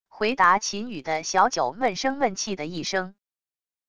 回答秦宇的小九闷声闷气的一声wav音频